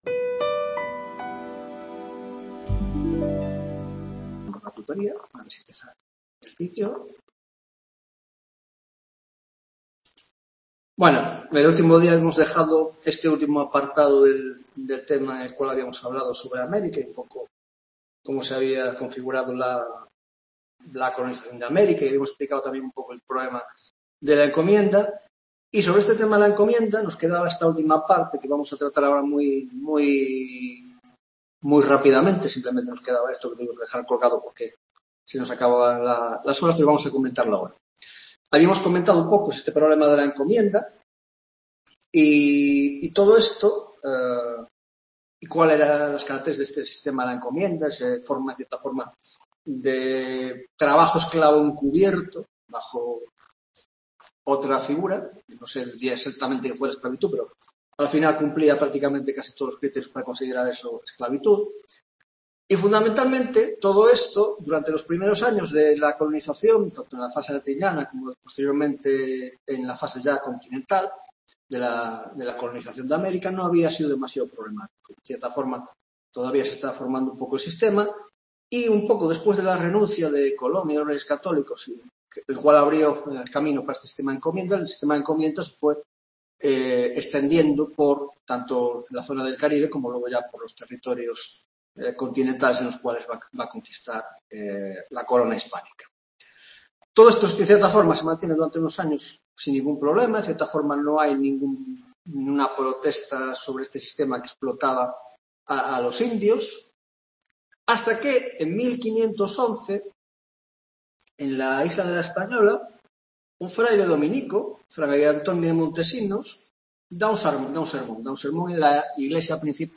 7ª Tutoría Historia Moderna (Grado de Antropología Social y Cultural)